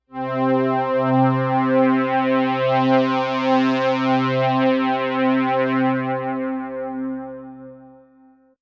SweepC4.wav